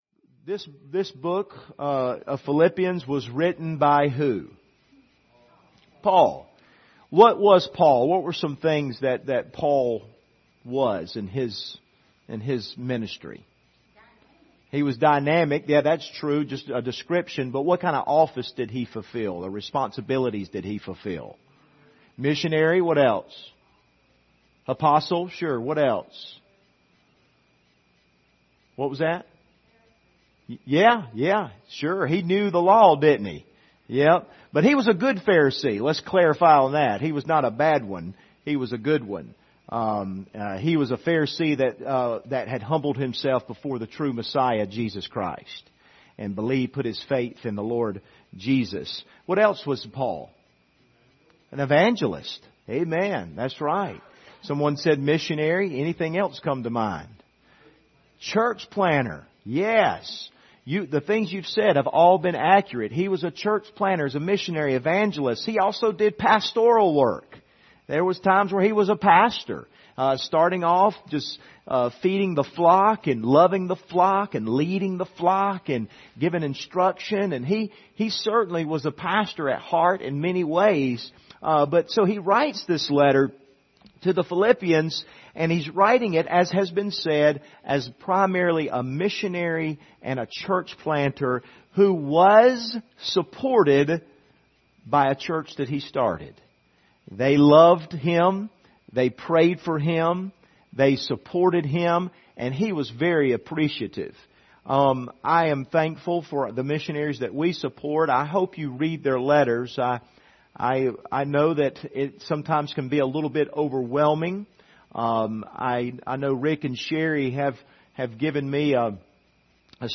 Service Type: Sunday Evening Topics: missions